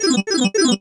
PM_Warp_Pipe.wav.mp3